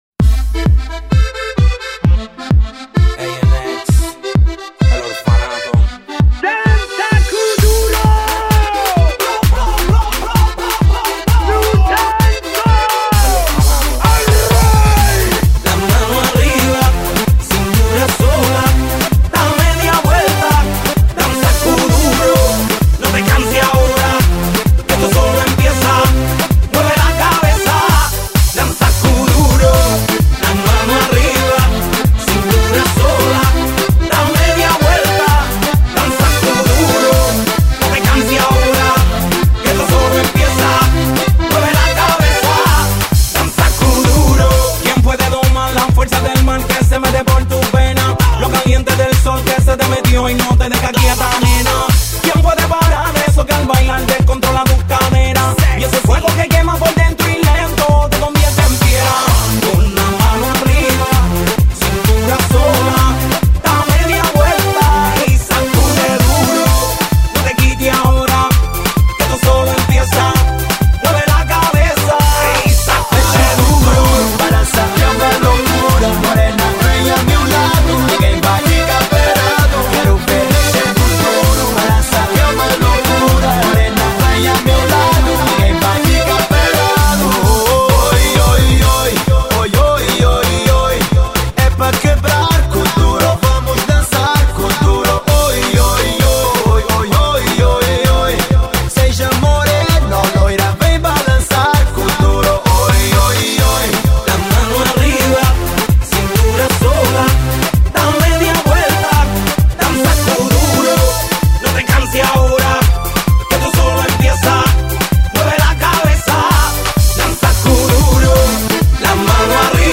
Rende bene il senso d’allegria…